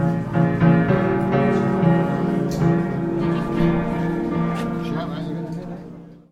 Trying out a piano for auction